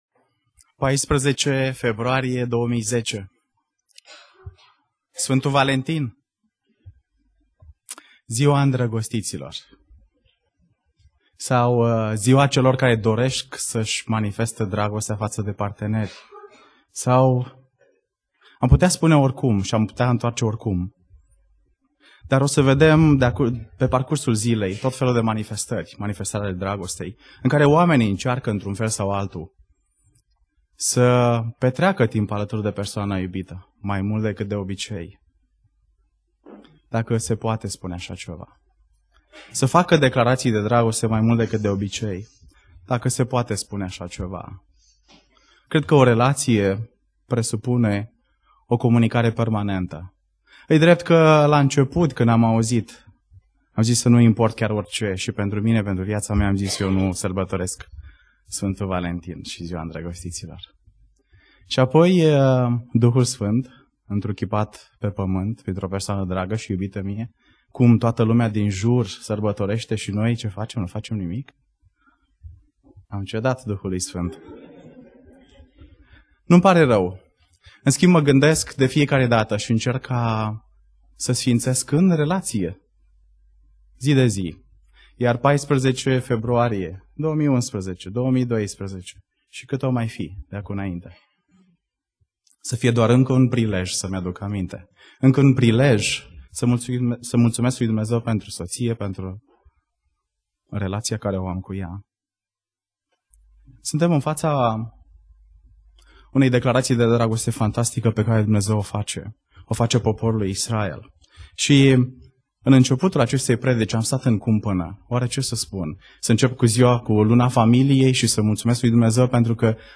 Predica Exegeza - Isaia 43-44